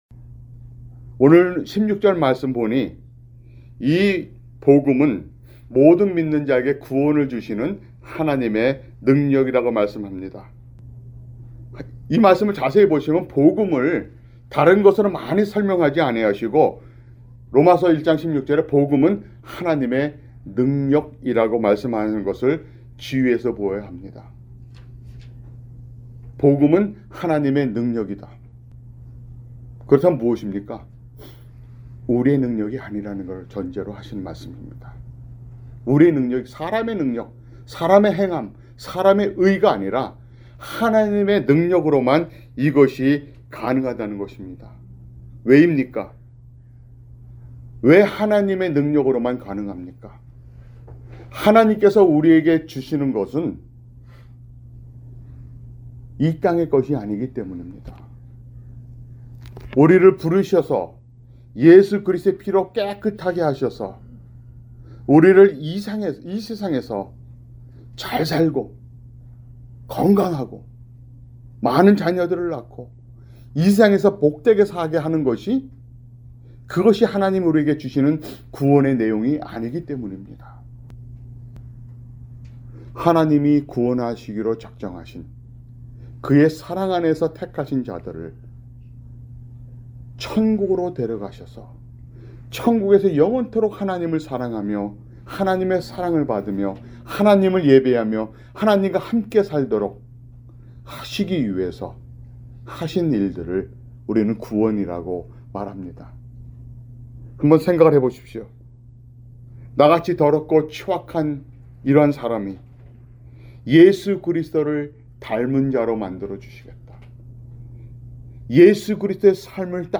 [주일설교] 로마서 (6)